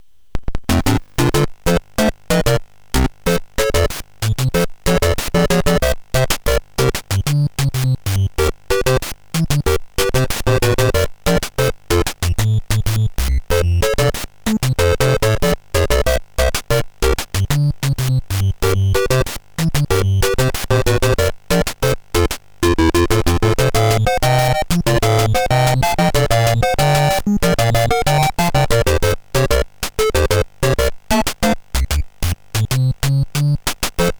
Но качество картинки и звука отвратительное, для сравнения картинки и аудио-файлы.